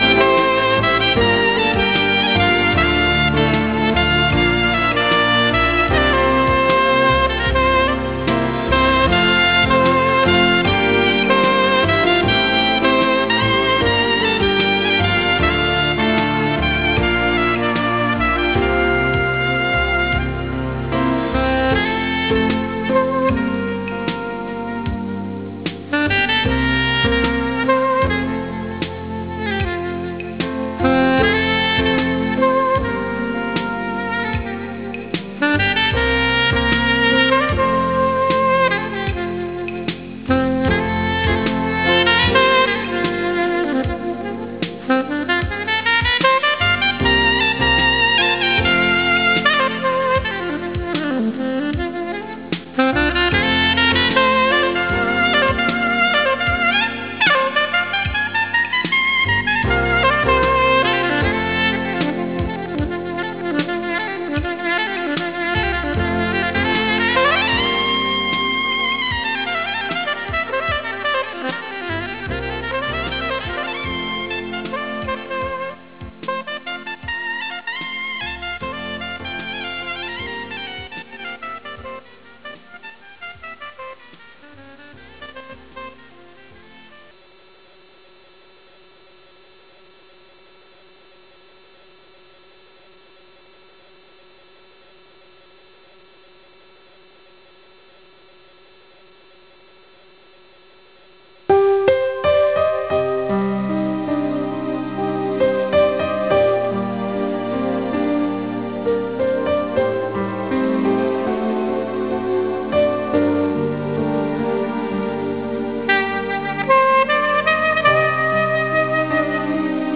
Sept-10-Telecon-IBM-Protecting-the-Business-with-End-to-End-High-Availability.wav